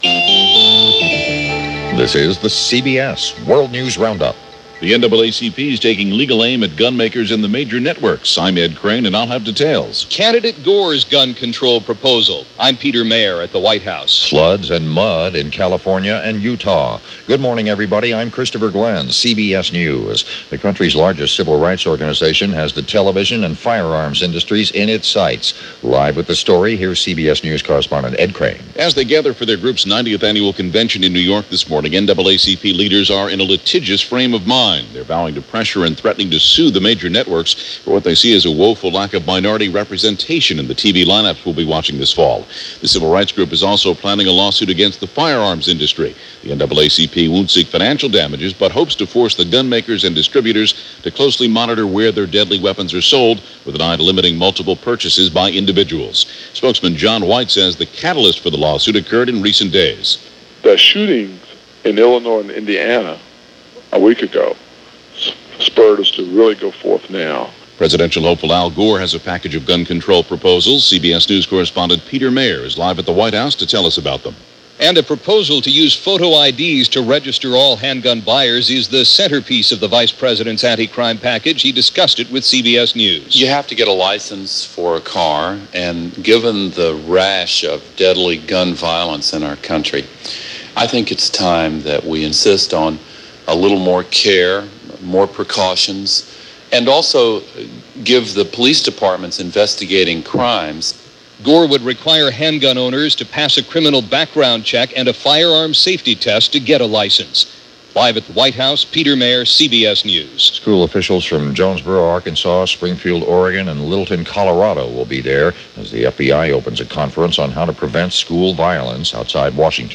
And that’s a small slice of what happened, this July 11, 1999 as presented by The CBS World News Roundup.